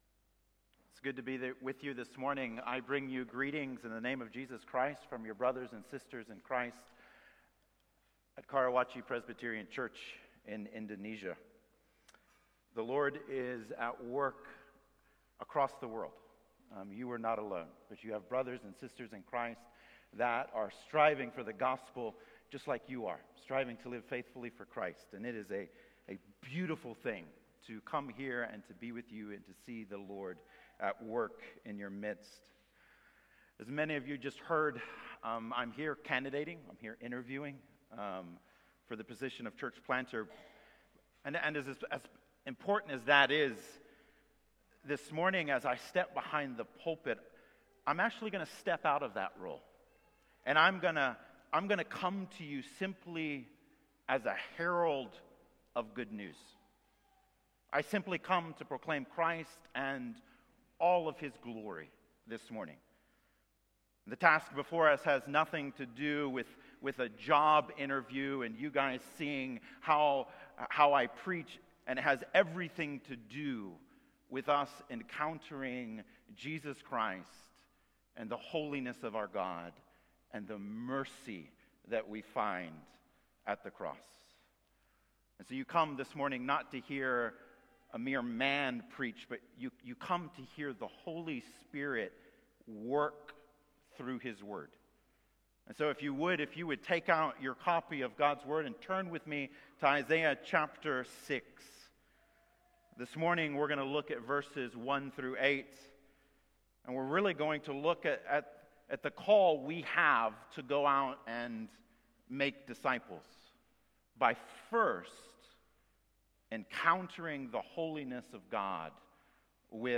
Isaiah 6:1-8 Service Type: Sunday Morning Download Files Bulletin « Providence